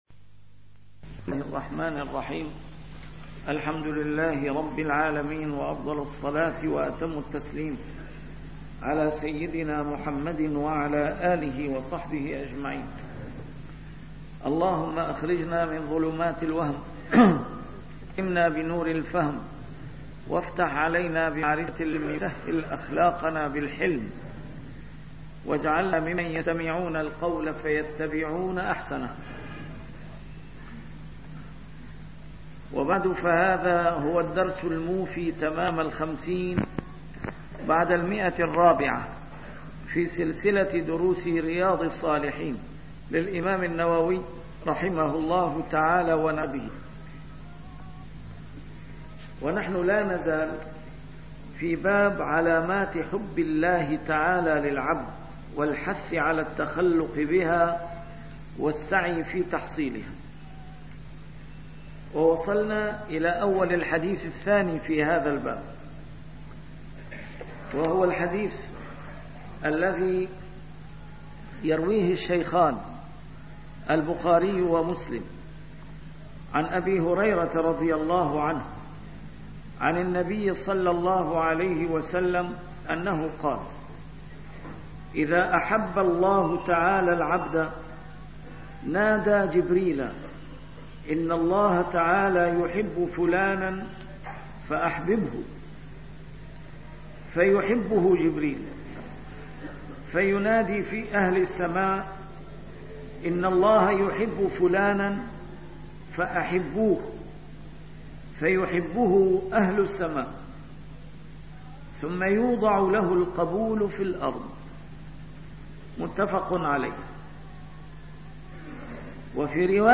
A MARTYR SCHOLAR: IMAM MUHAMMAD SAEED RAMADAN AL-BOUTI - الدروس العلمية - شرح كتاب رياض الصالحين - 450- شرح رياض الصالحين: علامات حب الله للعبد